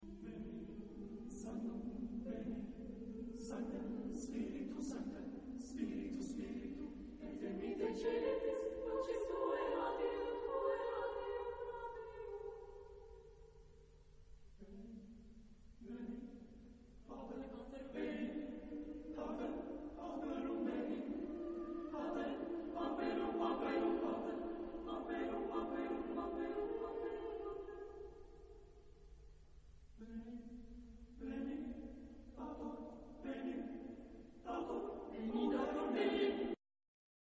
SATB (4 gemischter Chor Stimmen).